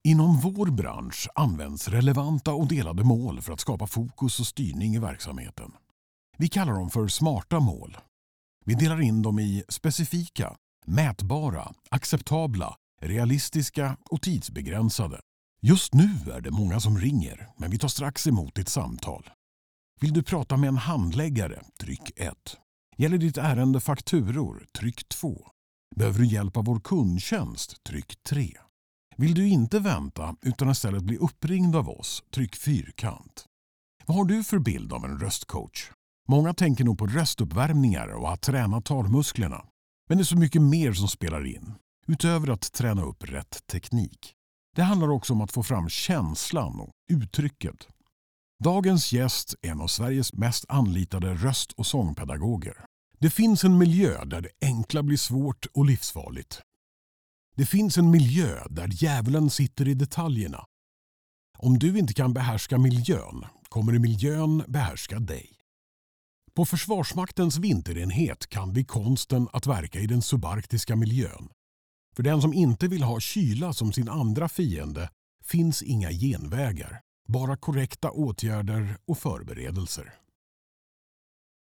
Male
Approachable, Authoritative, Confident, Conversational, Corporate, Deep, Energetic, Engaging, Versatile, Warm
Microphone: Austrian Audio OC18